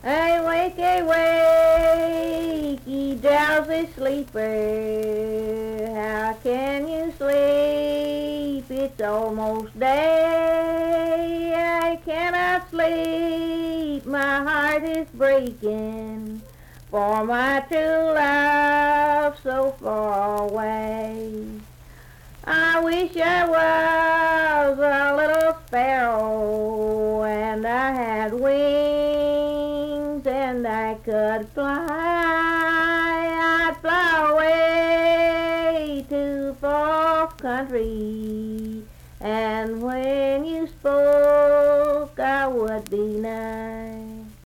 Unaccompanied vocal music
Voice (sung)
Richwood (W. Va.), Nicholas County (W. Va.)